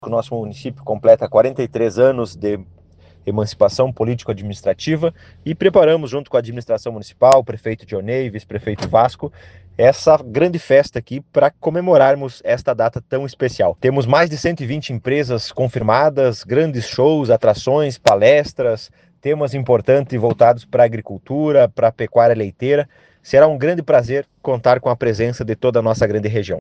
O presidente da feira, ex-prefeito, Adriano Marangon de Lima, ressalta que o evento também serve para marcar os 43 anos de emancipação de Jóia, cuja data vai transcorrer na próxima segunda-feira, dia 12.